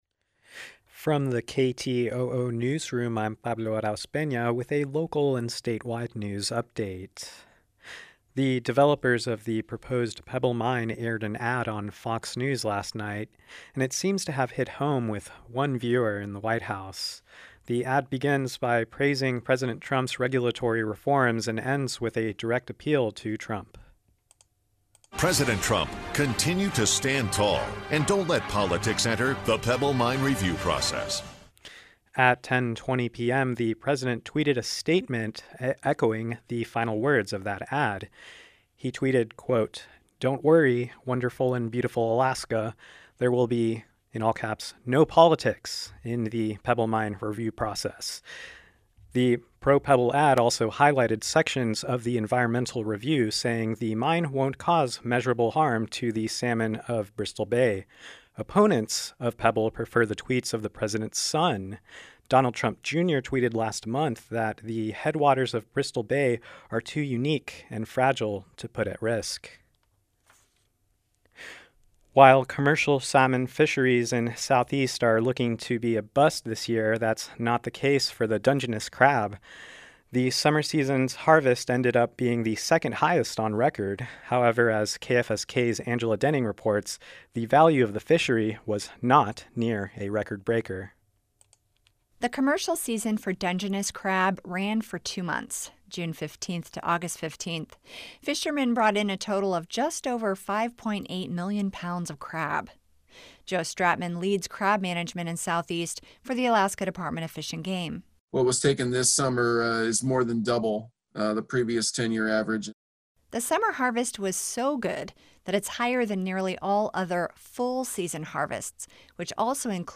Newscast – Thursday, September 17, 2020